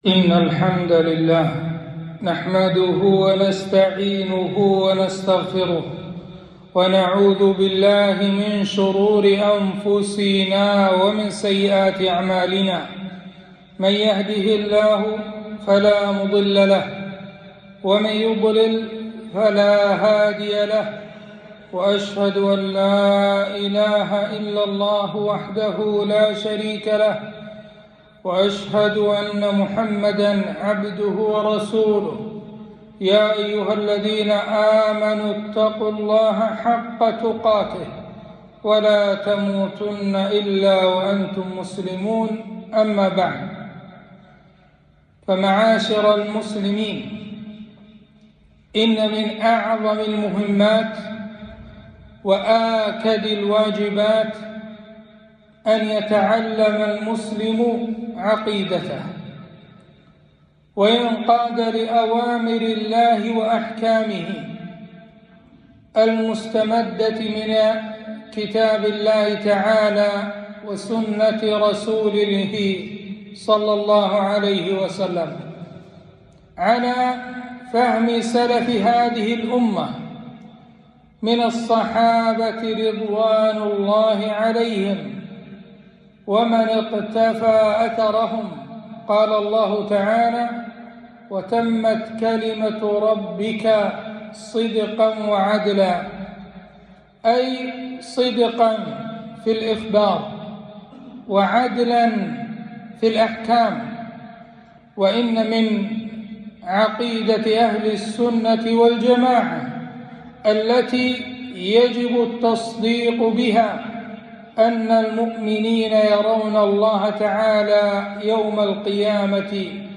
خطبة - رؤية الله تعالى في الآخرة